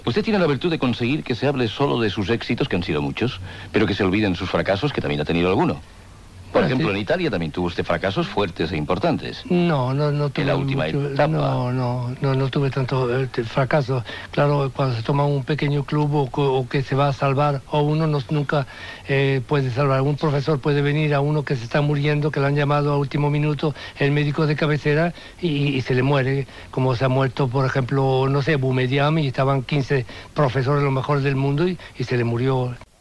Entrevista a l'entyrenador Helenio Herrera, sobre els seus fracassos
Informatiu